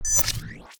UIMvmt_Futuristic Power PickUp 01.wav